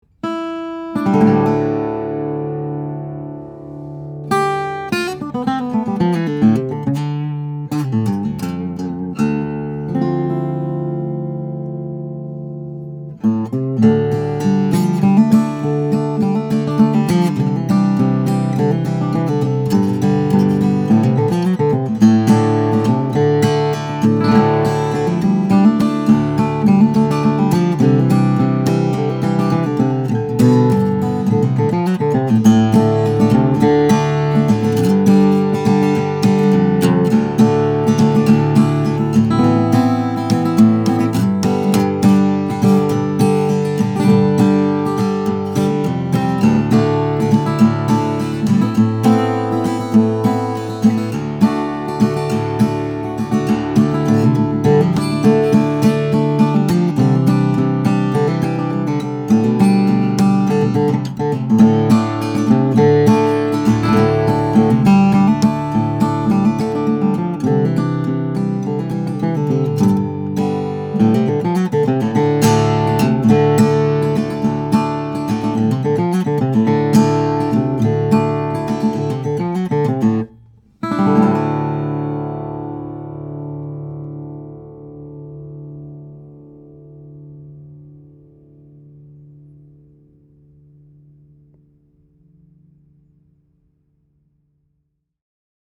This small-body instrument has a lot going on: a deep body and 13 frets to the body give it a bigger voice and feel than your typical 00-style guitar.